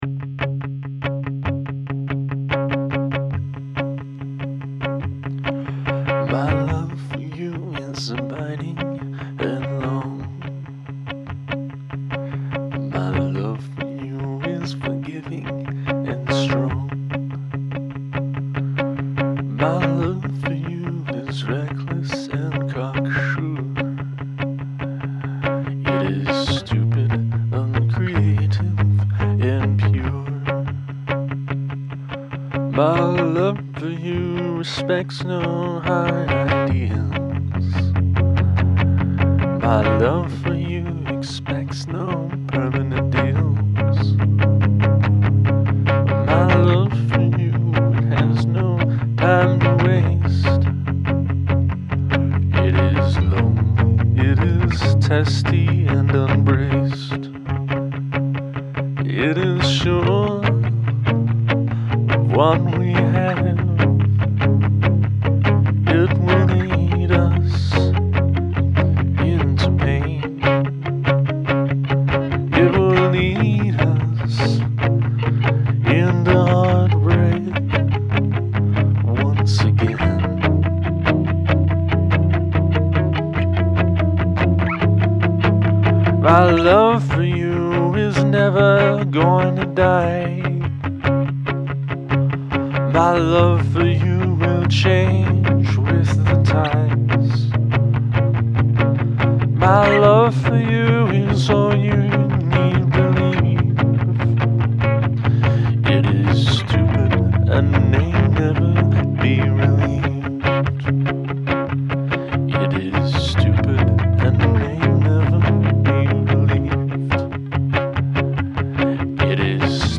Then this morning I took a moment to record a melody a cappella. Then this evening I had an idea for the guitars and recorded them.